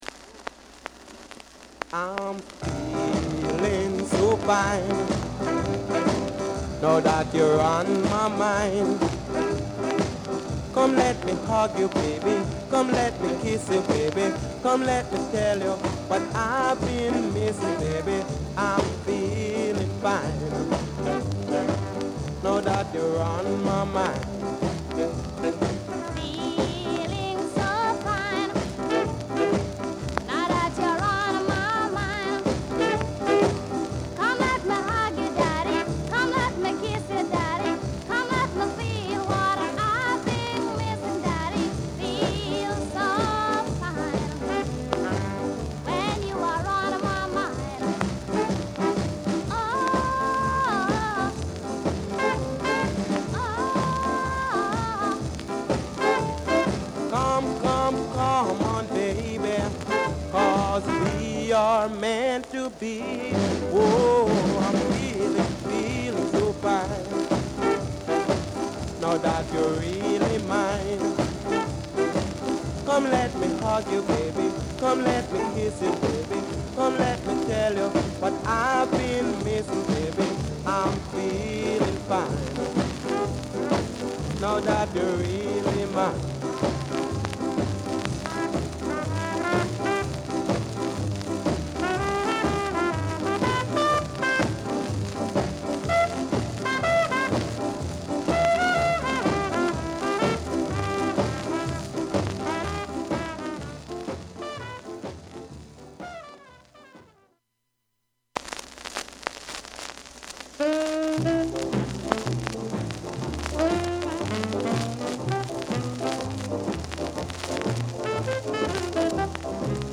Genre: Ska